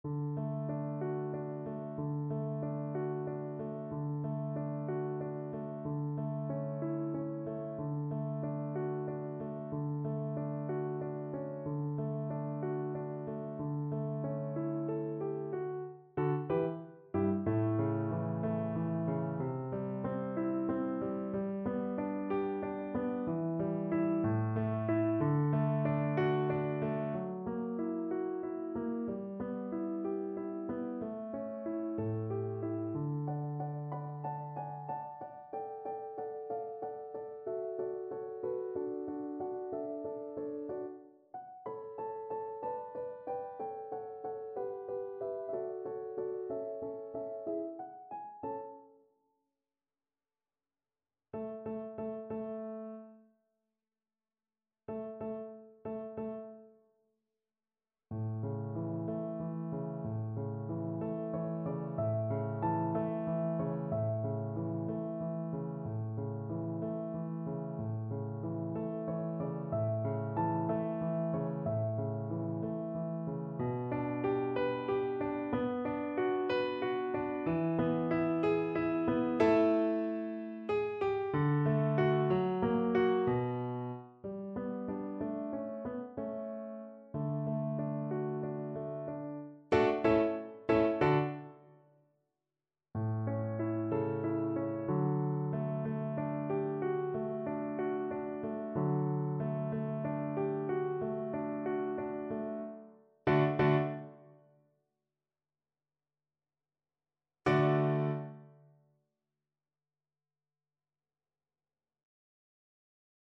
Moniuszko: Duet Hanny i Jadwigi (na flet i fortepian)
Symulacja akompaniamentu